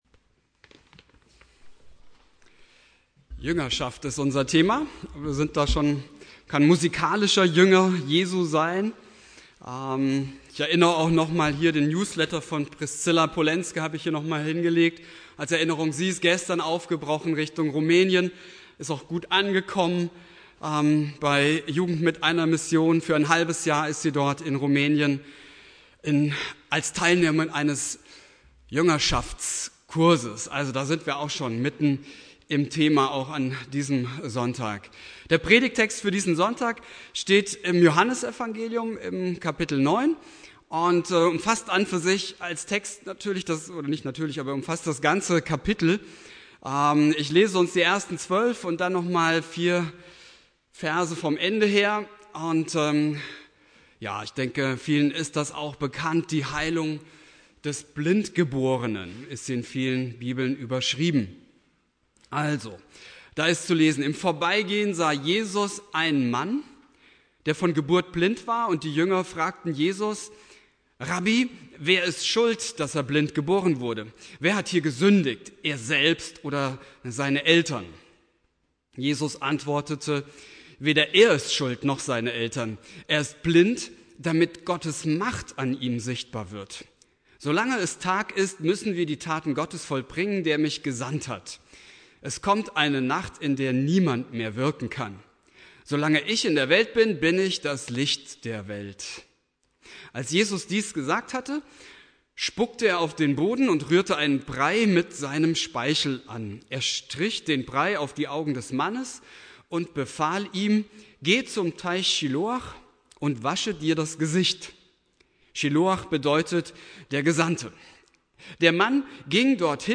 Thema: "Jünger Jesu machen" Inhalt der Predigt